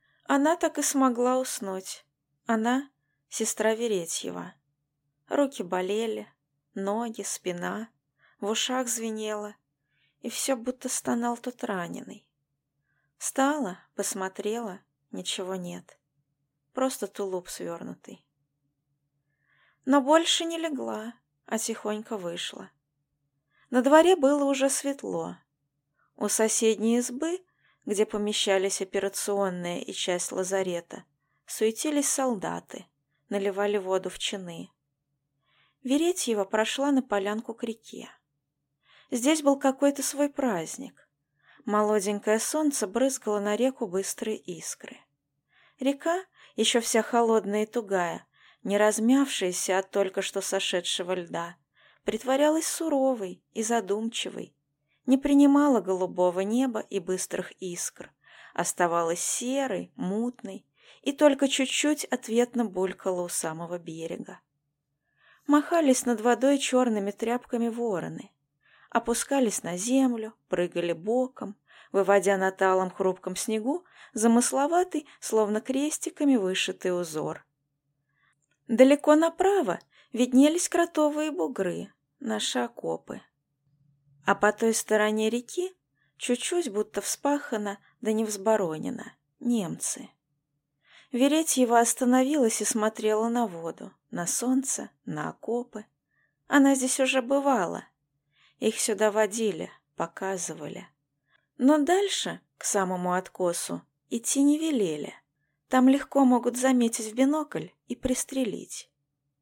Аудиокнига Лодка | Библиотека аудиокниг